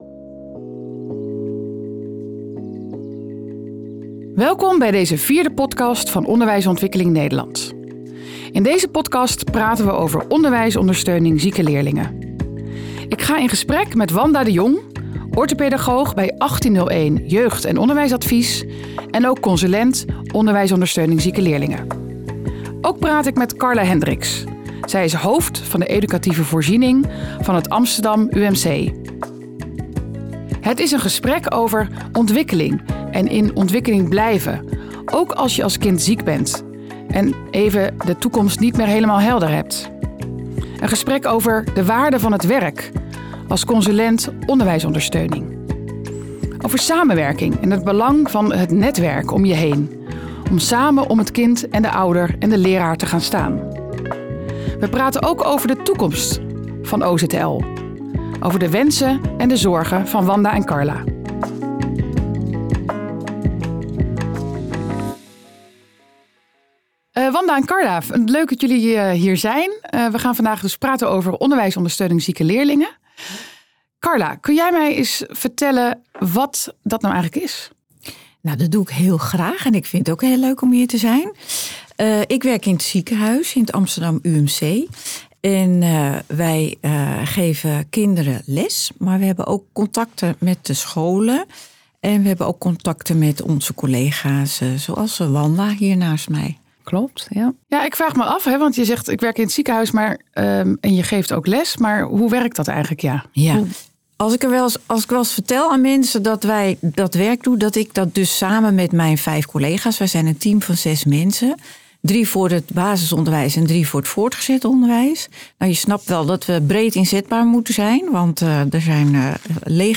Want wat gebeurt er wanneer iemand met een bovengemiddelde maatschappelijke drive in gesprek gaat met een expert die een vertaling naar de praktijk kan maken?